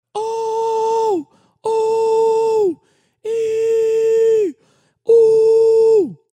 Вложения Верхний регистр.mp3 Верхний регистр.mp3 245,7 KB · Просмотры: 3.094 Фальцет.mp3 Фальцет.mp3 248,3 KB · Просмотры: 3.055